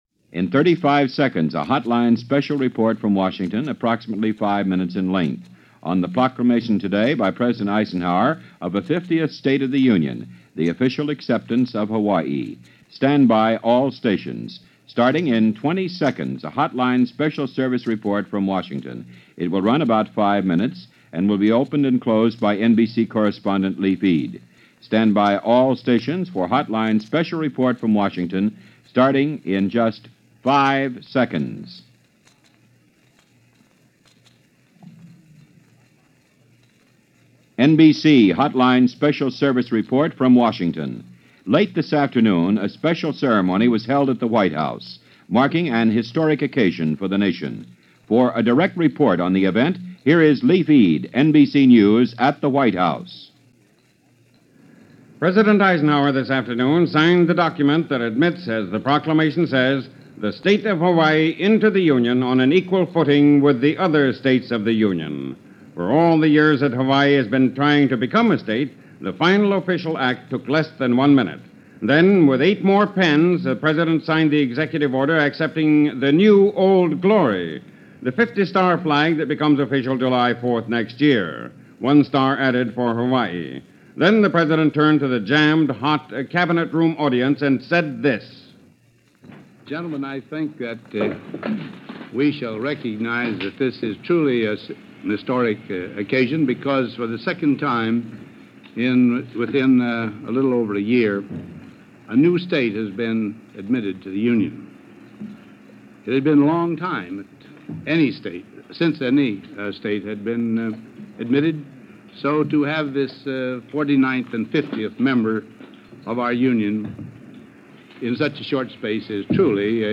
Here is that special report, as it happened on this day in 1959 via NBC Radio.